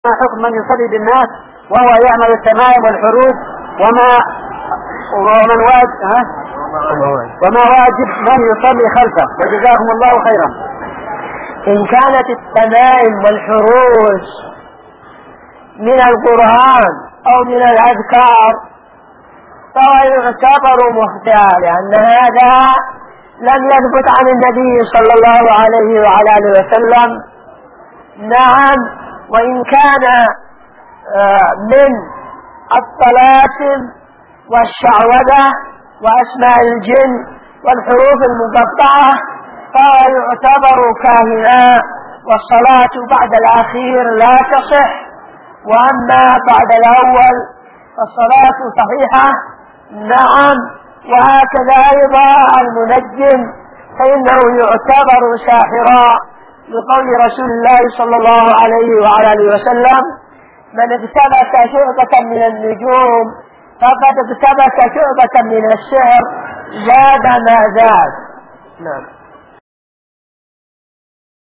------------- من شريط : ( أسئلة محاضرة علامات النبوة )